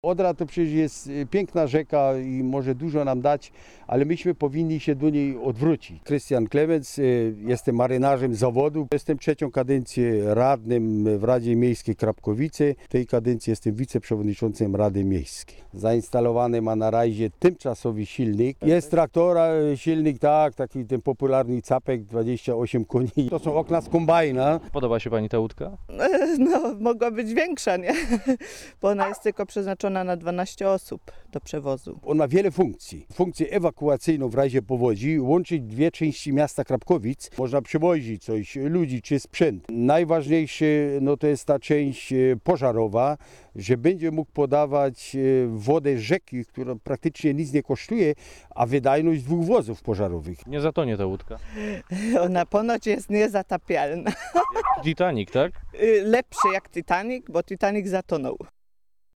Posłuchaj relacji opolskiego korespondenta Radia Zet